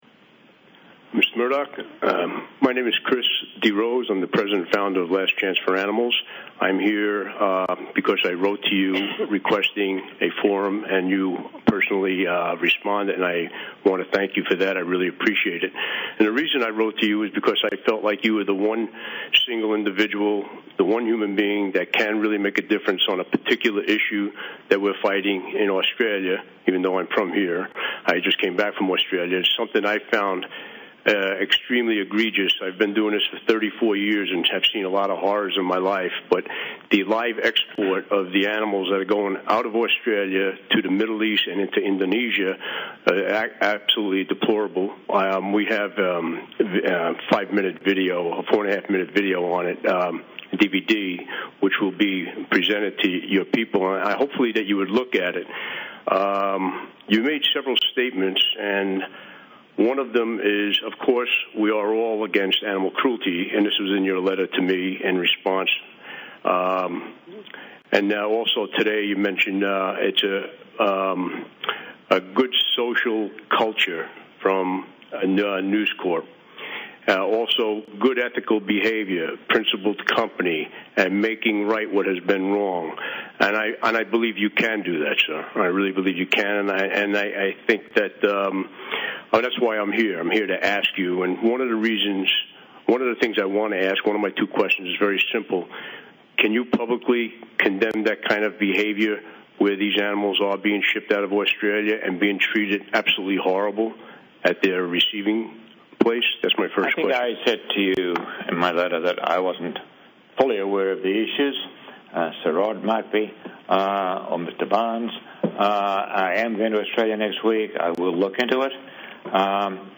Fully conscious trussed Australian sheep awaits throat cut (Photo: Animals Australia) Sheep have their throats cut while fully conscious and then are skinned on the spot in Kuwait slaughter yard (Photo: Animals Australia) Live export ship in Portland, Australia (Photo: Animal Liberation Victoria) LCA protesting at News Corp AGM October 21, 2011 Australian sheep disembarking live export ship
AT NEWS CORPS AGM